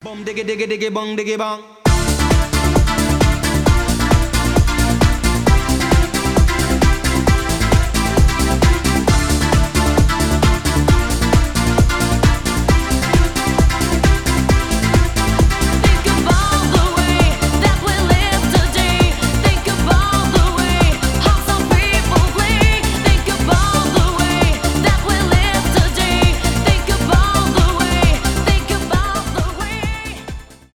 euro house
танцевальные